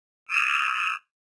richiami (60 KB) simili a quelli dell'Airone Cenerino, ma è generalmente molto più silenziosa.
garzetta.wav